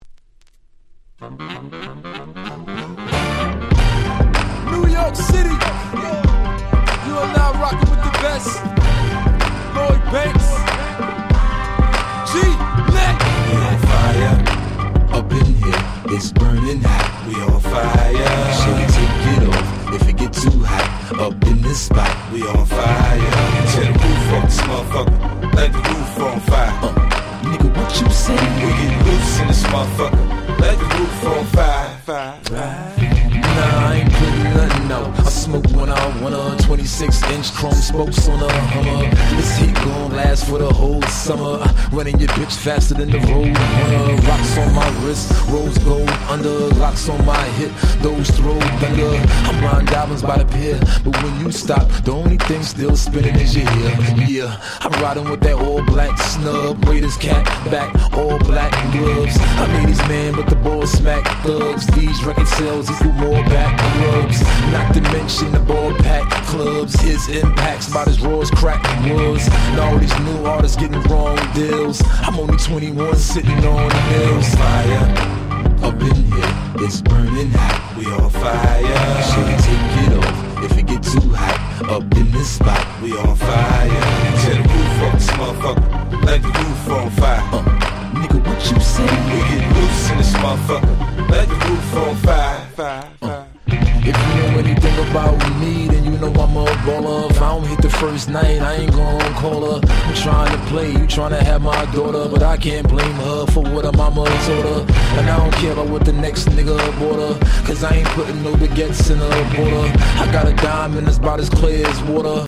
04' Smash Hit Hip Hop !!